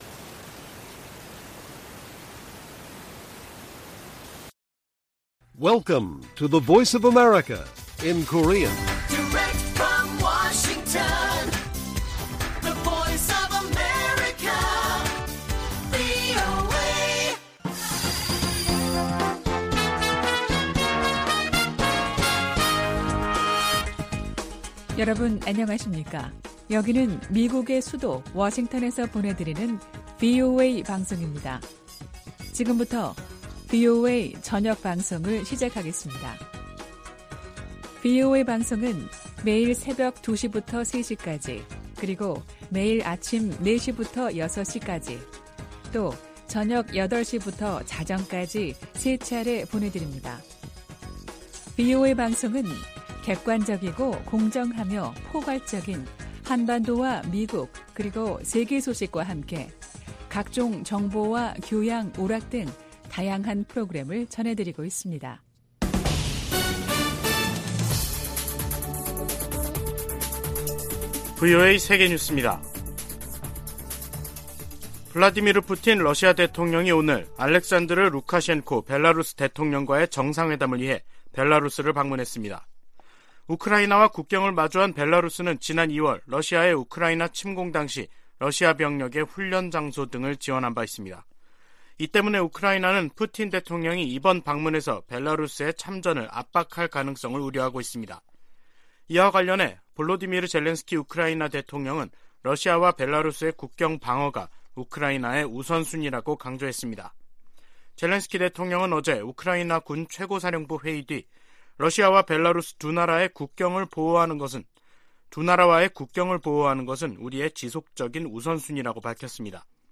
VOA 한국어 간판 뉴스 프로그램 '뉴스 투데이', 2022년 12월 19일 1부 방송입니다. 북한은 내년 4월 군 정찰위성 1호기를 준비하겠다고 밝혔지만 전문가들은 북한의 기술 수준에 의문을 제기하고 있습니다. 미국 국무부는 북한이 고출력 고체 엔진실험을 감행한 데 대해 국제사회가 북한에 책임을 묻는 일을 도와야 한다며 단합된 대응을 강조했습니다.